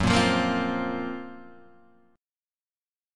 GbM#11 chord